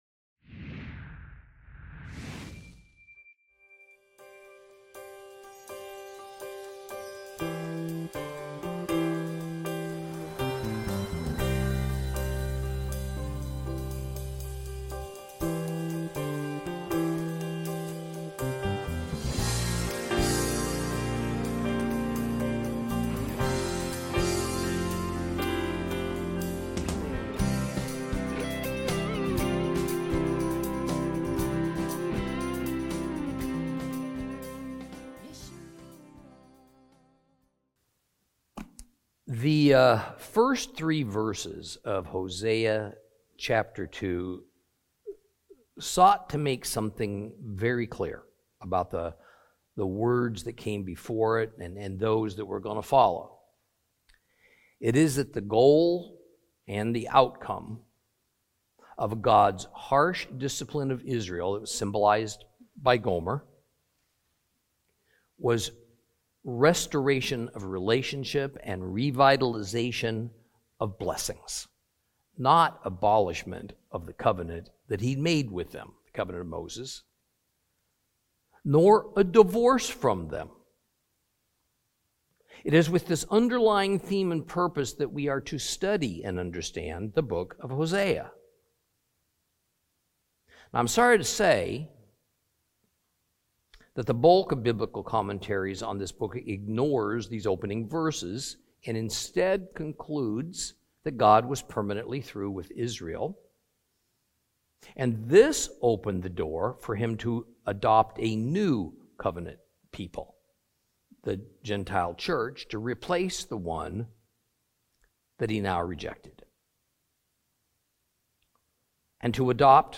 Teaching from the book of Hosea, Lesson 5 Chapter 2 continued.